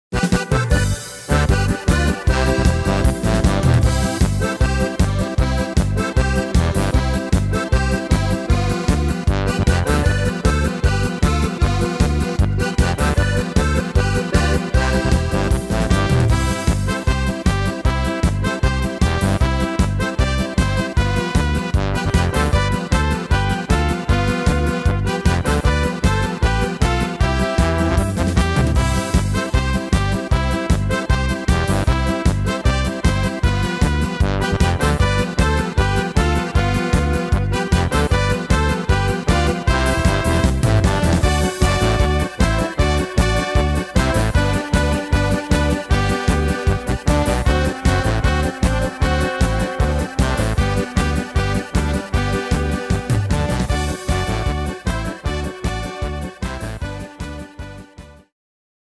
Tempo: 154 / Tonart: C -Dur